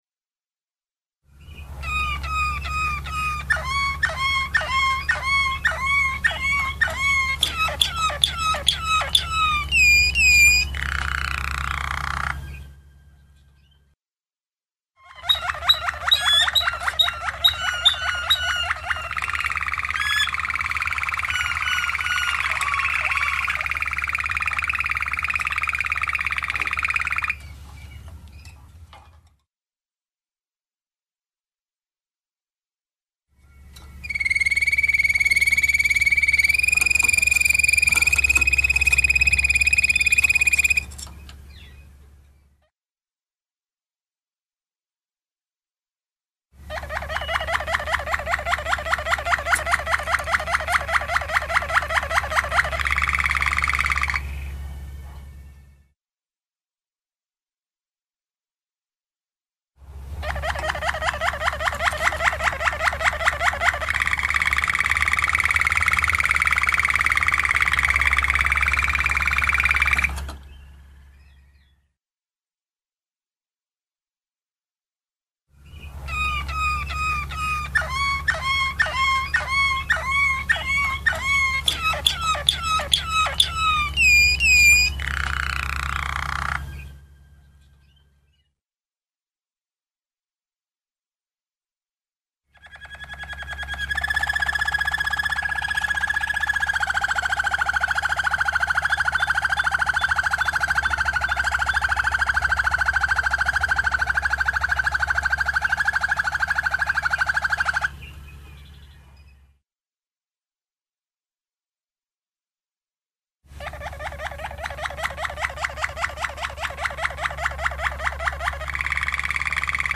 Suara Burung Kenari Waterslager
3. Suara Kenari Waterslanger Gacor Mewah
3.-Suara-Kenari-Waterslanger-Gacor-Mewah.mp3